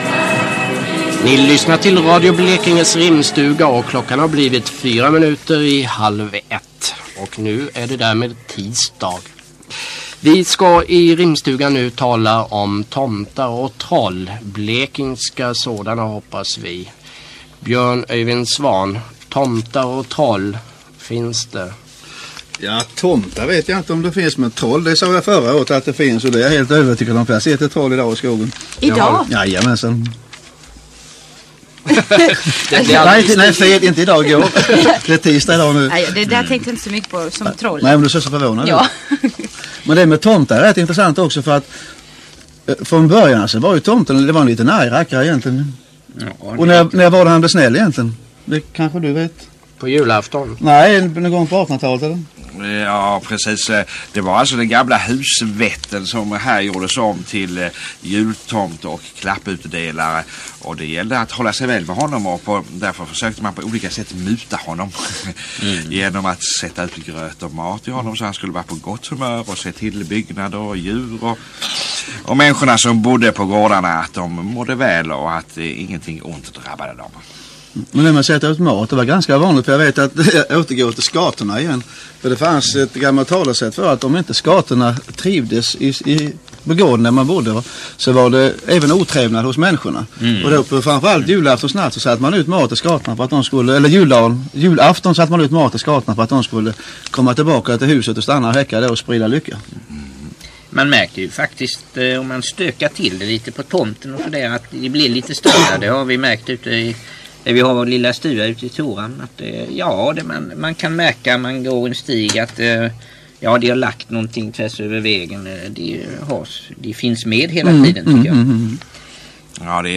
Kort utsnitt ur en av Radio Blekinges första rimstugor i början av 1980-talet.